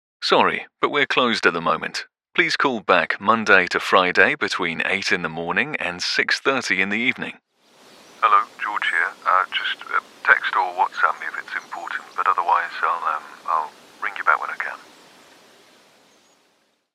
You will really hear the smile in this voice! Described as well spoken with a clear voice that cuts through, with the ability to sound young or mature, versatile yet believable.